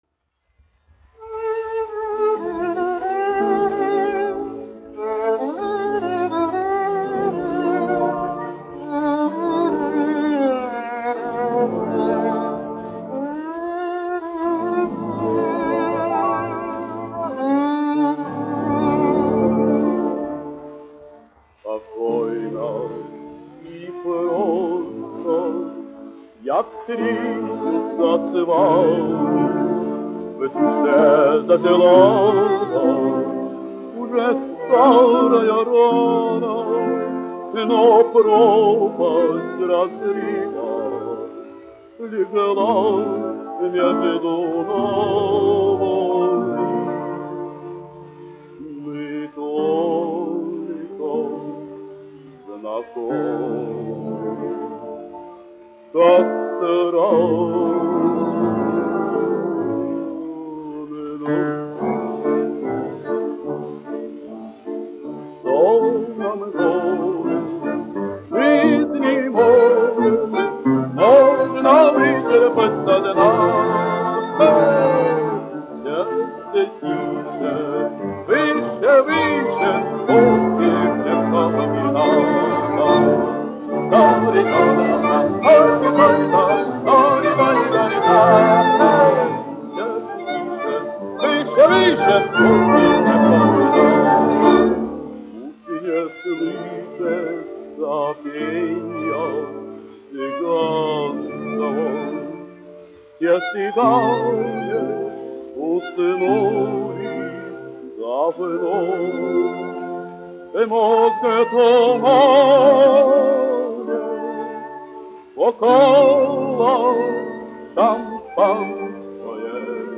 dziedātājs
1 skpl. : analogs, 78 apgr/min, mono ; 25 cm
Romances (mūzika)
Populārā mūzika
Skaņuplate